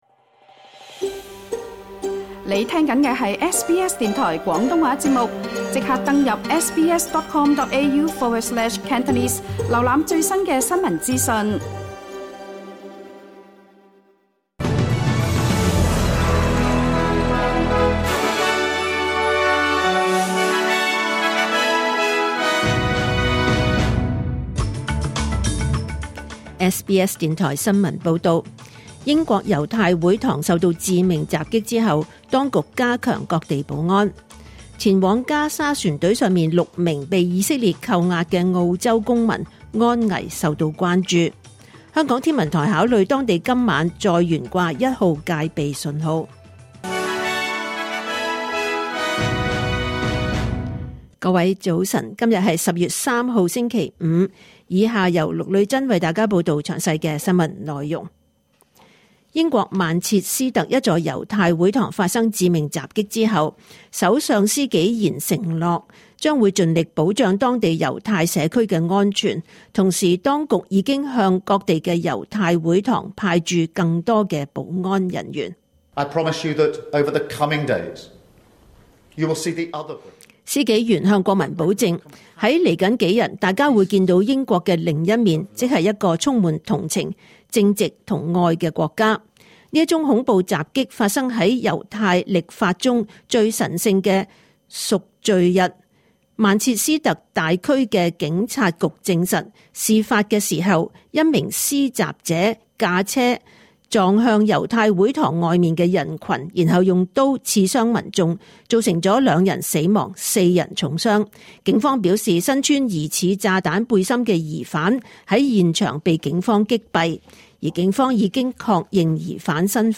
2025年10月3日 SBS 廣東話節目九點半新聞報道。